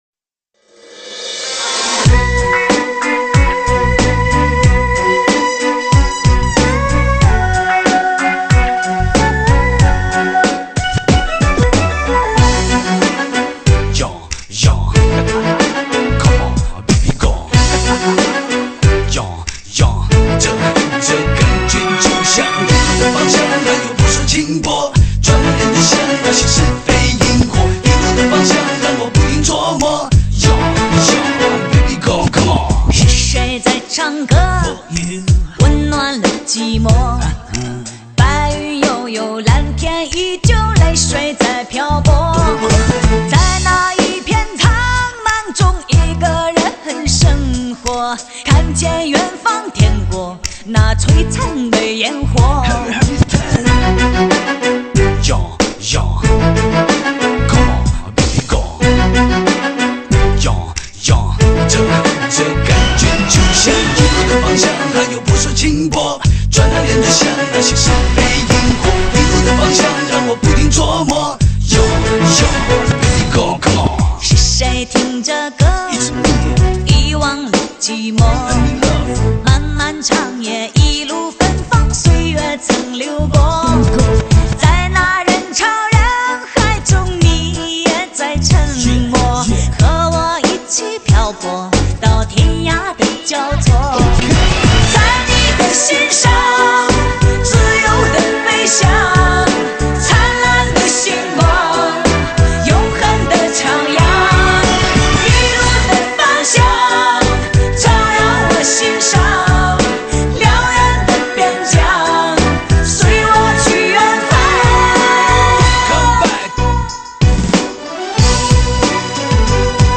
带来超乎想象的逼真和震撼性的5.1环绕声音乐,是歌迷收藏之品.